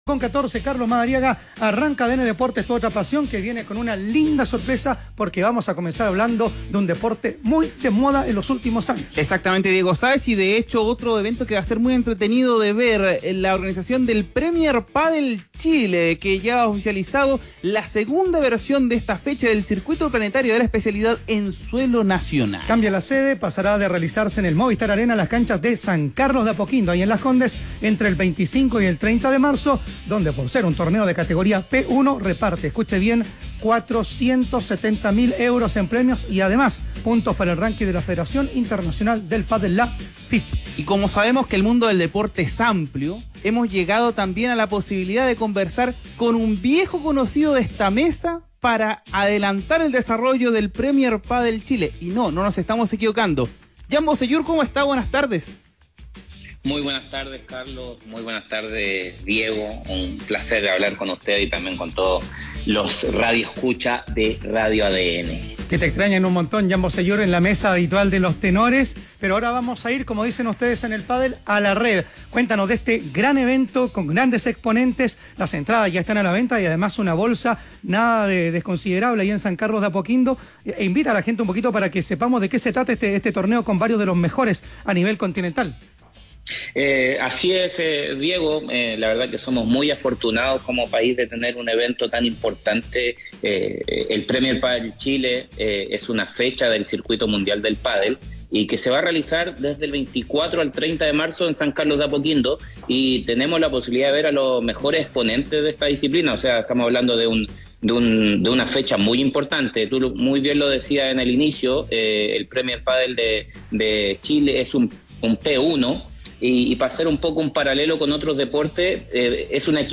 En conversación con ADN TOP KIA, el bicampeón de América con La Roja también se mostró tranquilo ante las bajas de la selección adulta para el amistoso con Panamá.